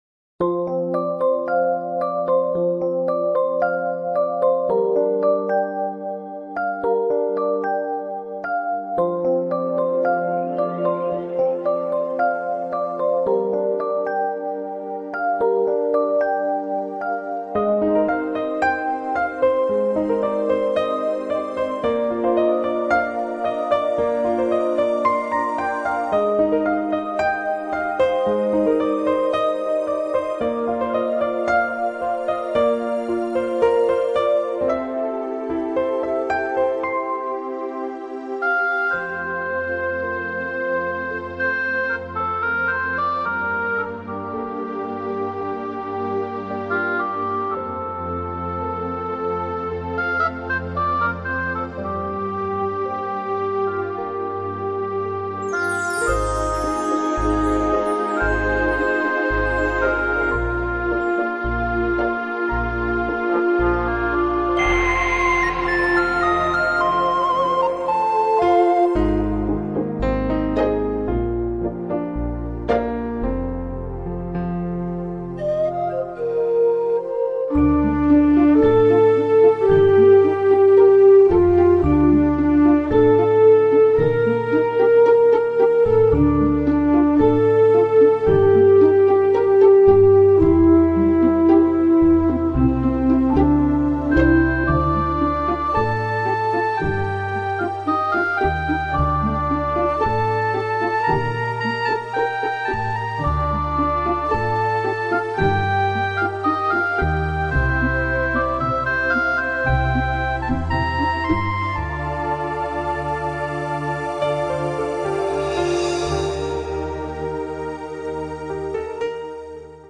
规则的节奏，简单柔美的旋律，传达可爱保守的音乐风格，好比室女纤尘不染的心灵。